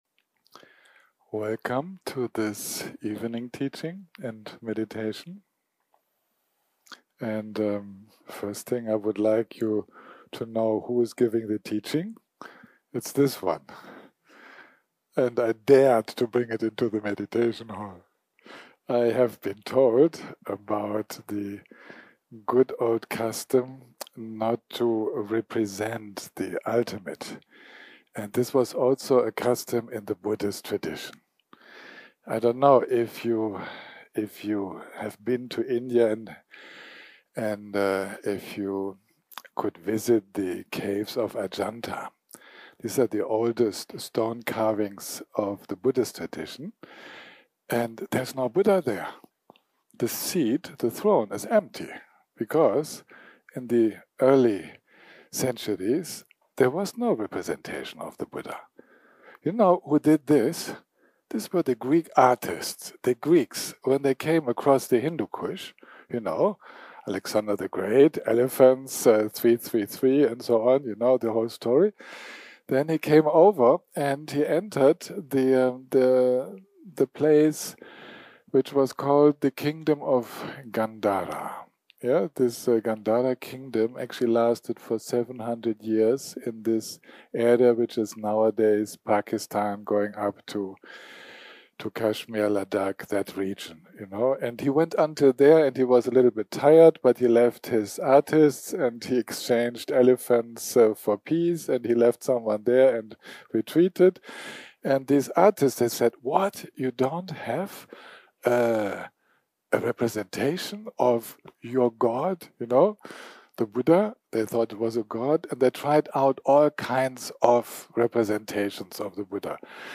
סוג ההקלטה: שיחות דהרמה שפת ההקלטה